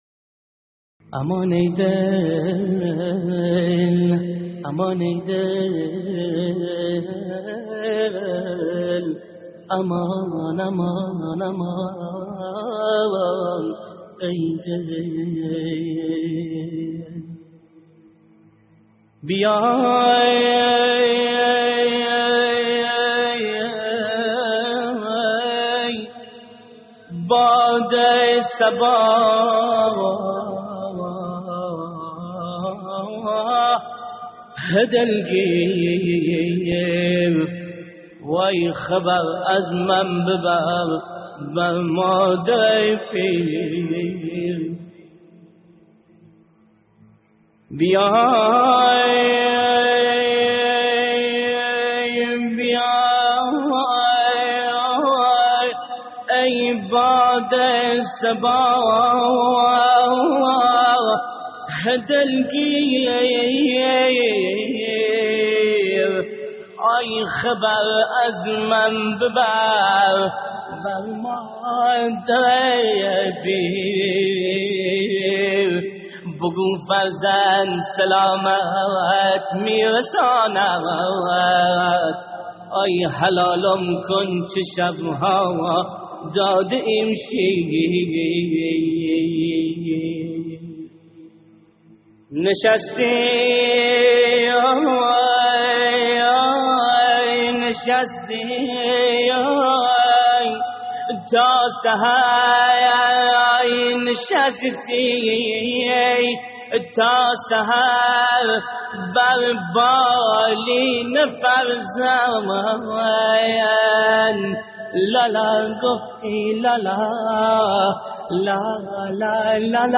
استديو «يا والدي» فارسي لحفظ الملف في مجلد خاص اضغط بالزر الأيمن هنا ثم اختر